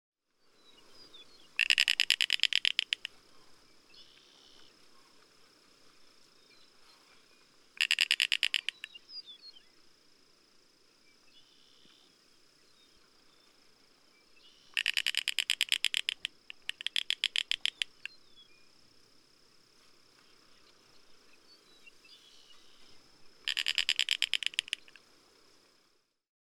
На этой странице собраны разнообразные звуки коршуна – от резких криков до переливчатых трелей.
Запись коршуна-слизнееда из Флориды